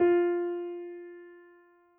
piano_053.wav